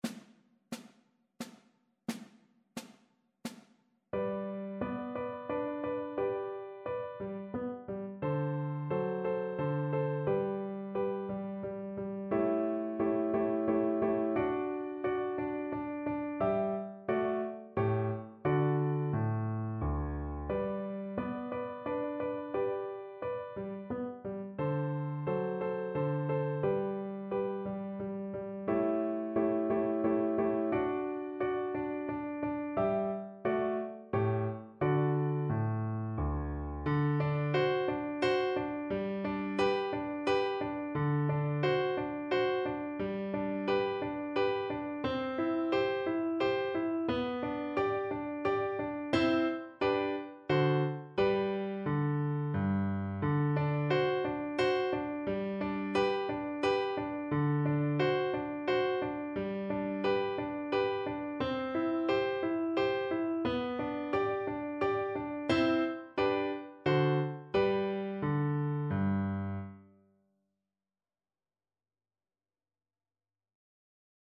Mozart: Menuet z opery Don Giovanni (na wiolonczelę i fortepian)
Symulacja akompaniamentu